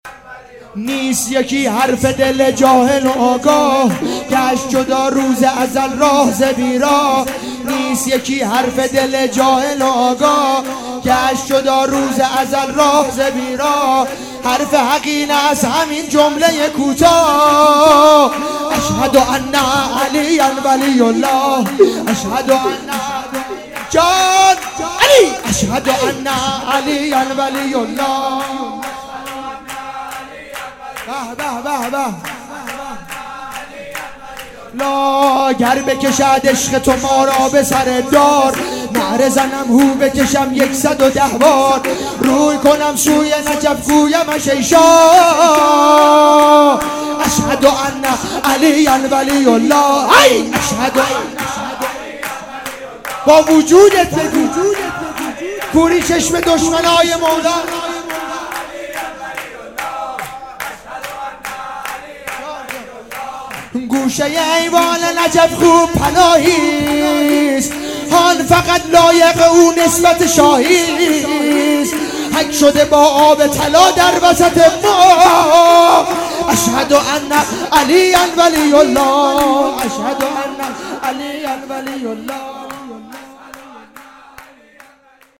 جشن ولادت امام جواد علیه السلام